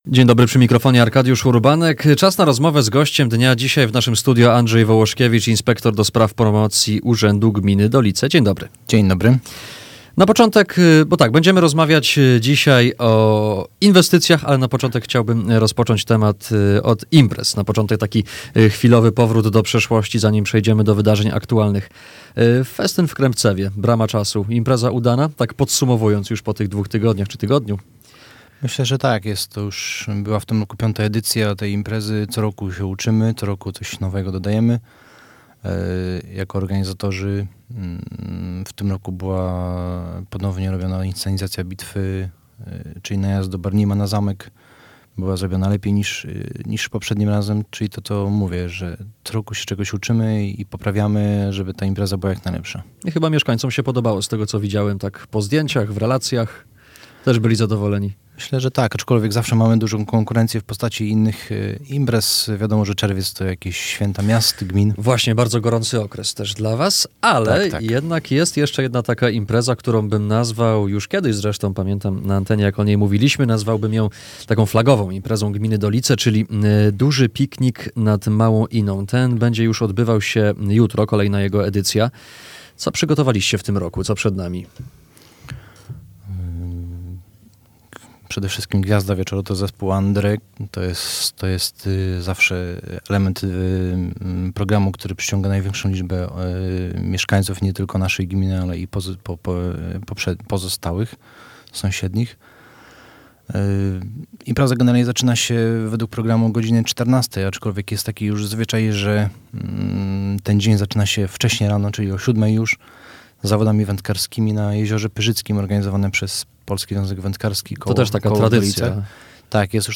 Z naszym gościem rozmawialiśmy między innymi o gminnych inwestycjach, a także o jutrzejszym Dużym Pikniku nad Małą Iną.